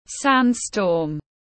Sand storm /ˈsænd.stɔːm/